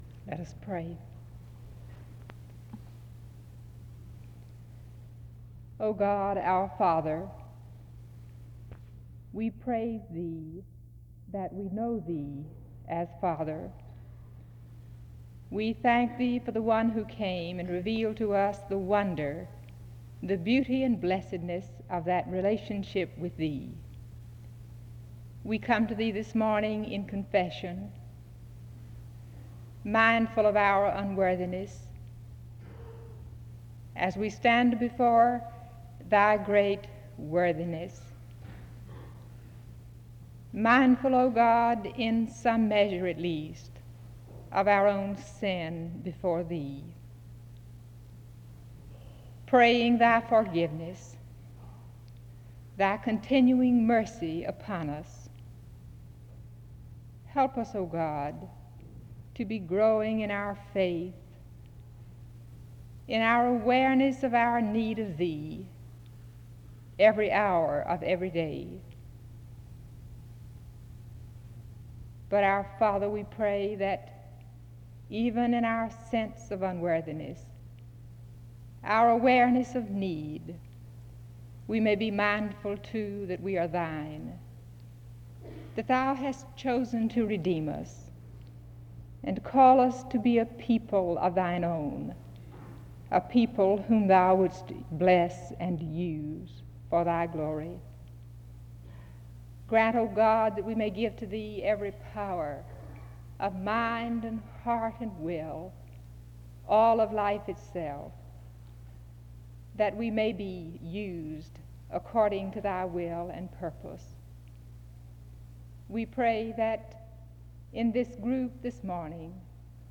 Download .mp3 Description The service begins with prayer (00:00-03:49).
The service continues with choral singing (08:07-09:56).